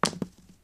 dieThrow1.ogg